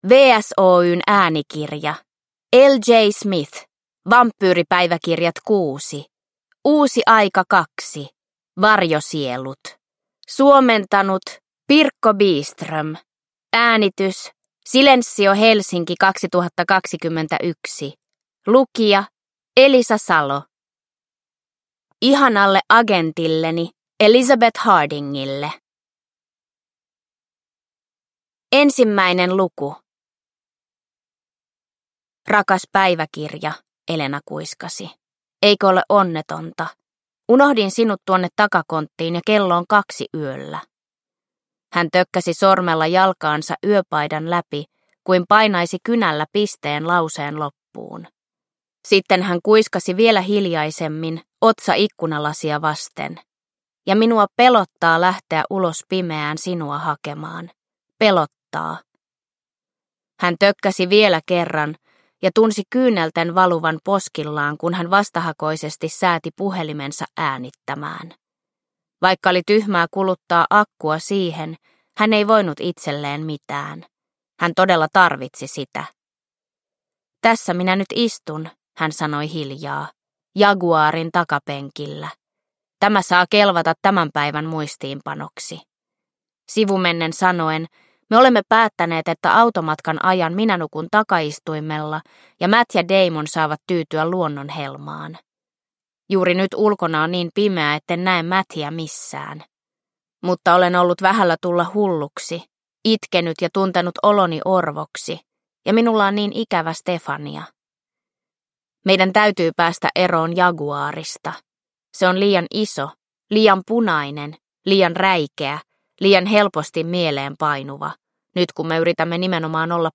Uusi aika: Varjosielut – Ljudbok – Laddas ner